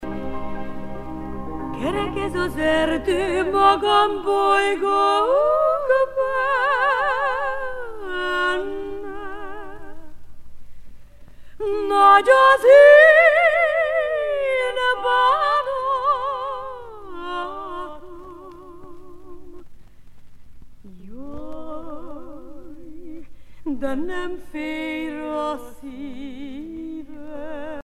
folk songs
Pièce musicale éditée